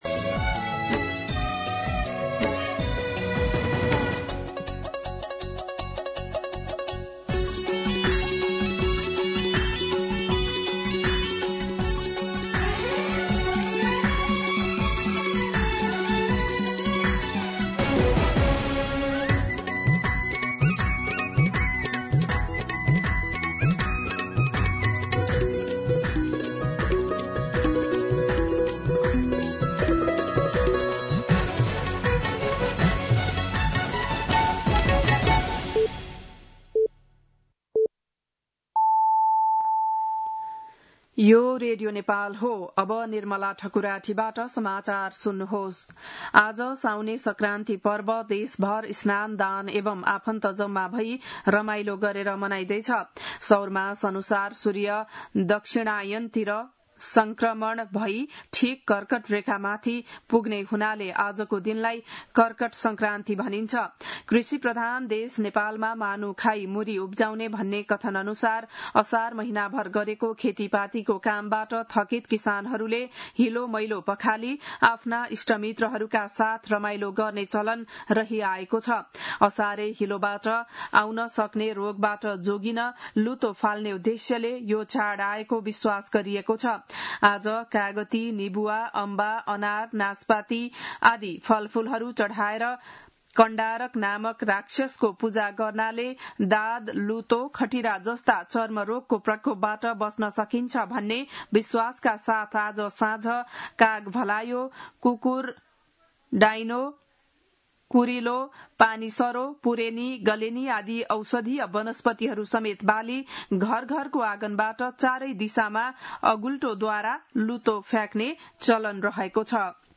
बिहान ११ बजेको नेपाली समाचार : १ साउन , २०८२